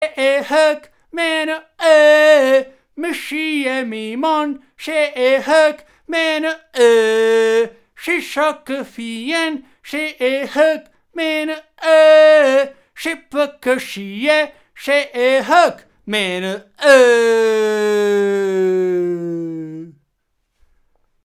3) Canto.